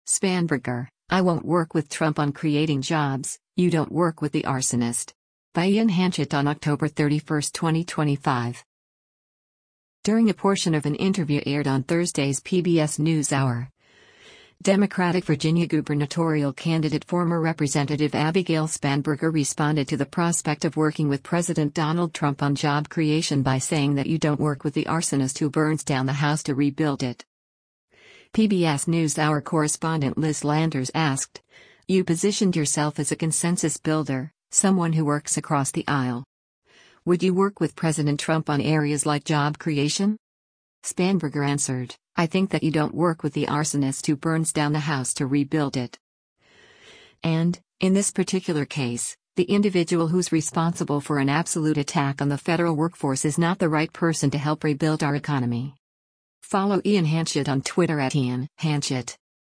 During a portion of an interview aired on Thursday’s “PBS NewsHour,” Democratic Virginia gubernatorial candidate former Rep. Abigail Spanberger responded to the prospect of working with President Donald Trump on job creation by saying that “you don’t work with the arsonist who burns down the house to rebuild it.”